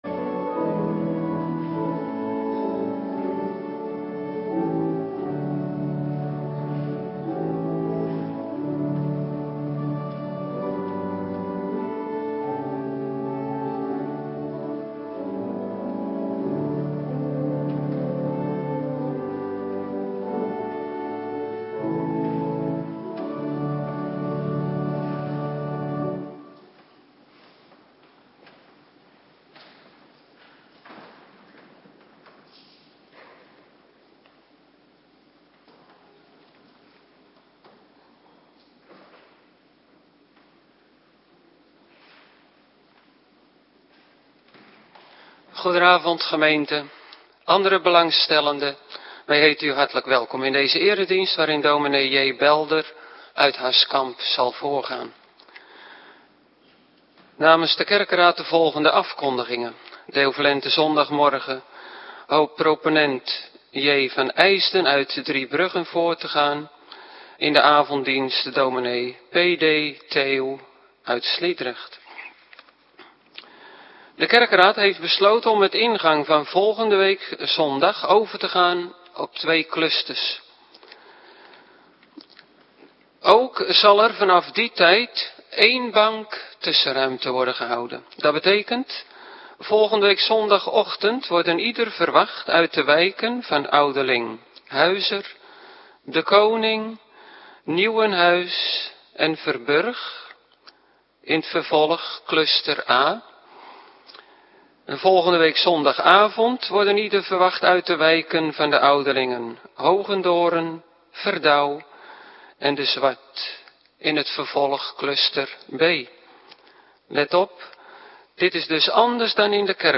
Avonddienst Nabetrachting Heilig Avondmaal - Cluster 1
Locatie: Hervormde Gemeente Waarder